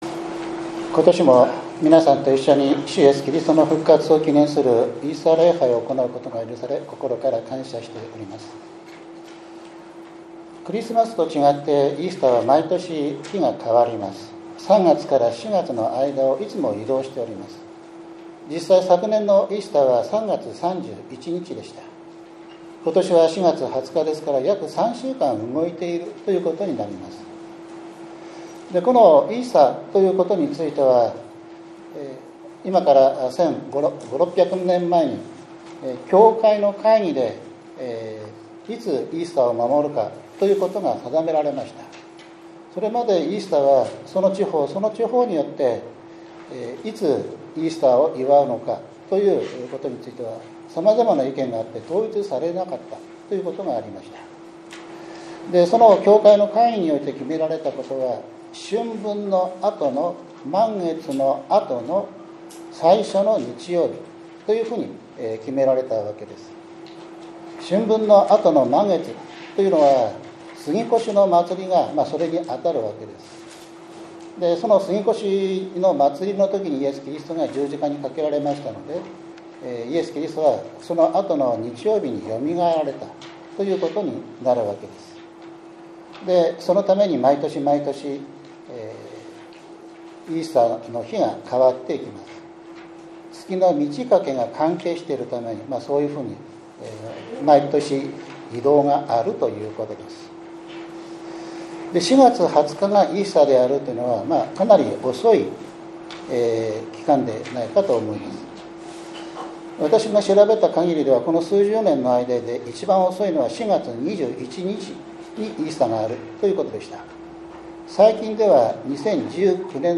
４月２０日（日）イースター礼拝